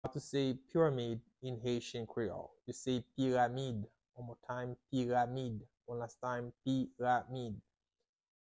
How to say "Pyramid" in Haitian Creole - "Piramid" pronunciation by a native Haitian tutor
“Piramid” Pronunciation in Haitian Creole by a native Haitian can be heard in the audio here or in the video below: